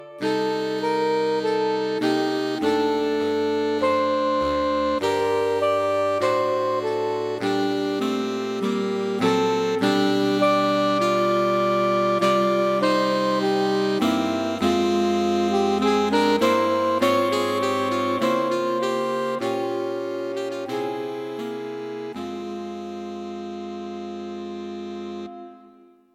Saxophone Quartet for Concert performance
Soprano, Alto, Tenor and Baritone Sax.